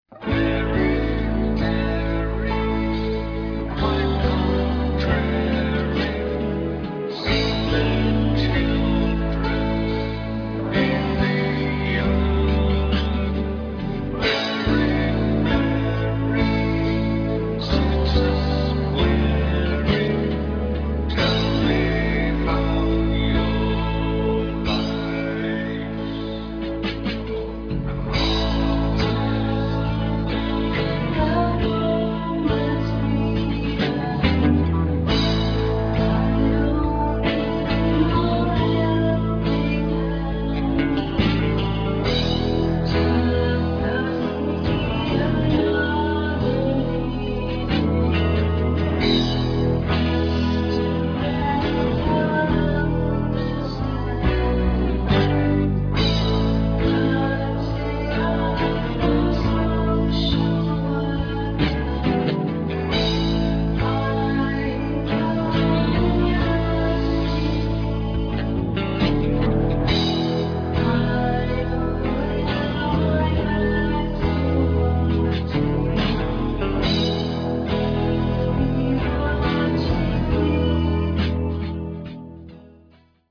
stereo, 5,5 Khz, 32 Kbps, file size: 326 Kb